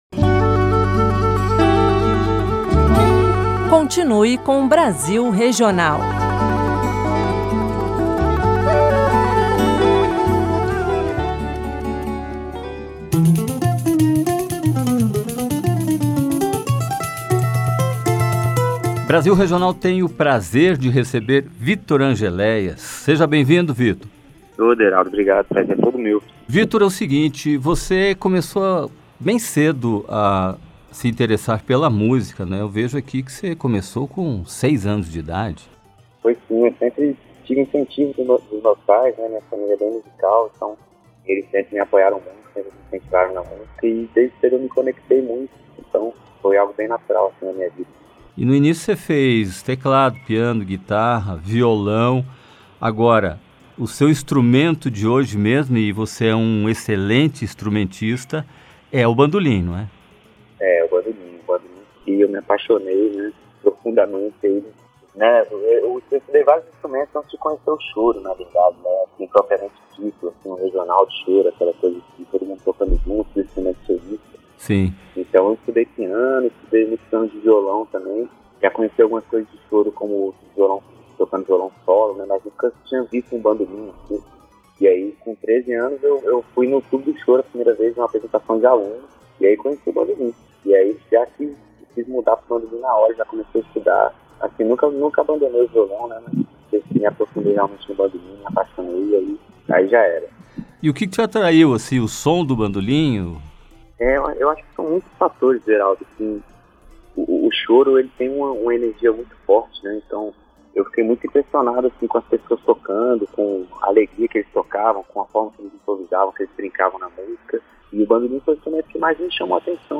bandolinista de 10 cordas